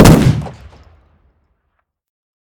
shotgun-shot-9.ogg